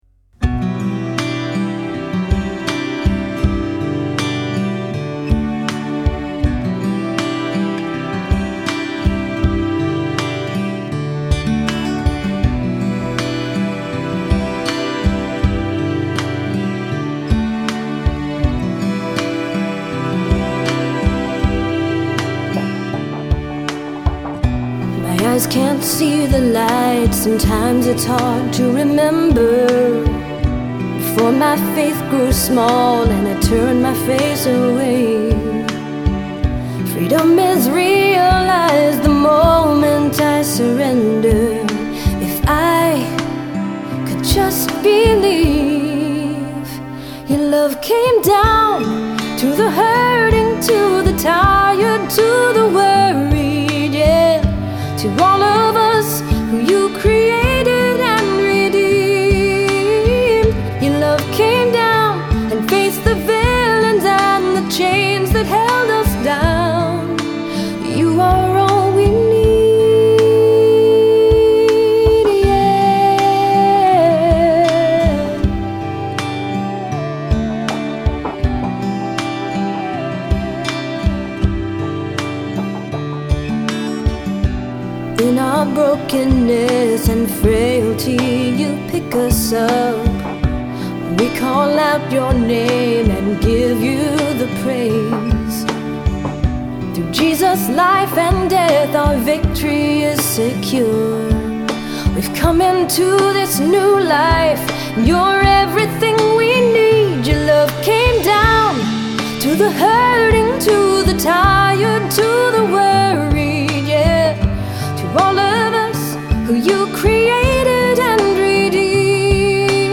original songs for times of worship